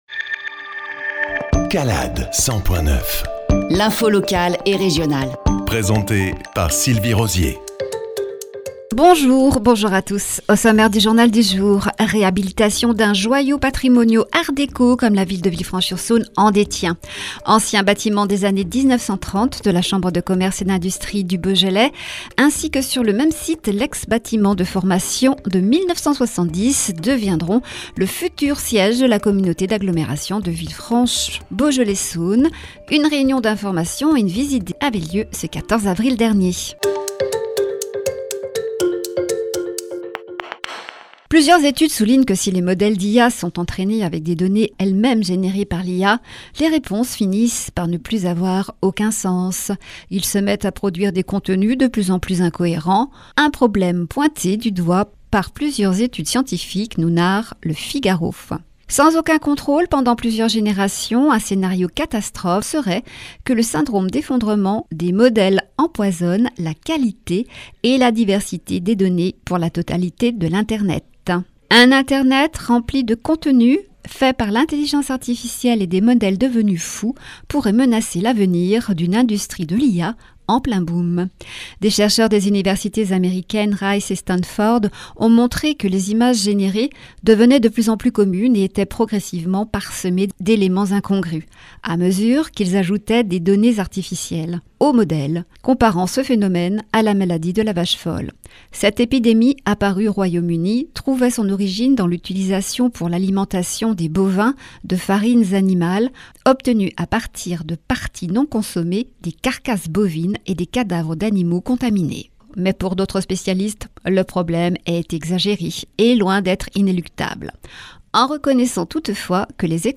JOURNAL – 150425